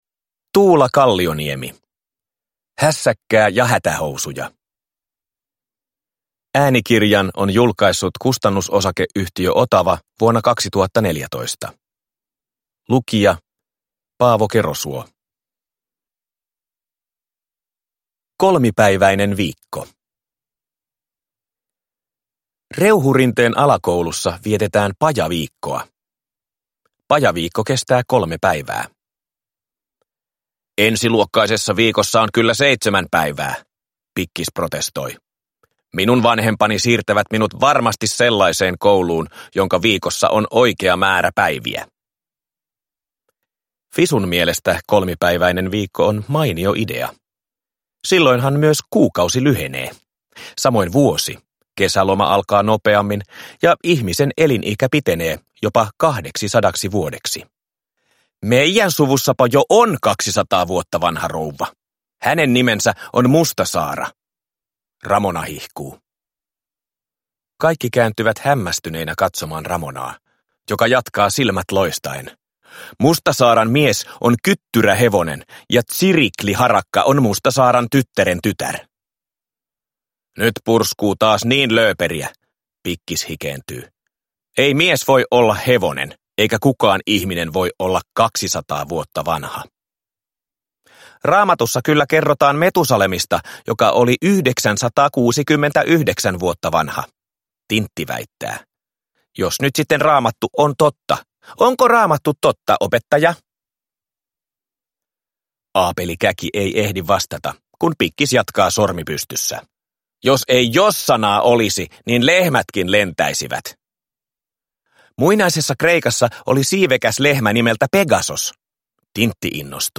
Hässäkkää ja hätähousuja – Ljudbok – Laddas ner